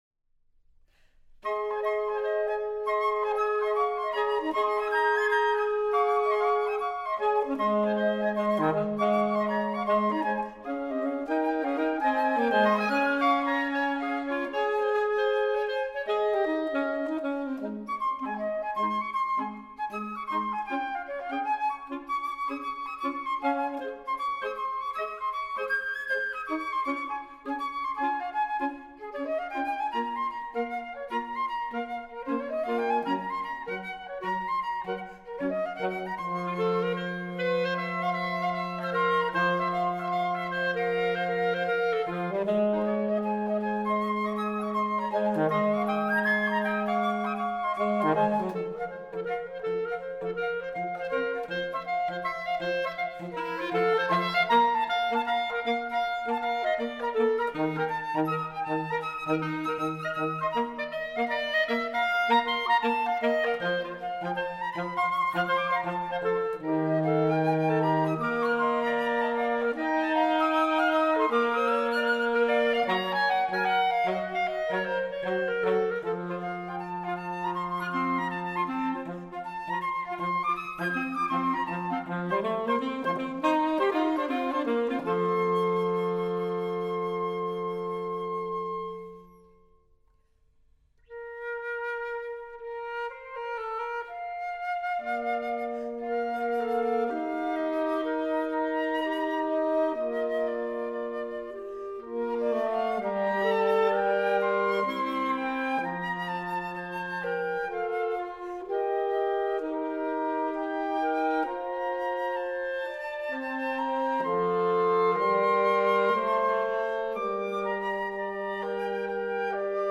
Partitions pour trio flexible.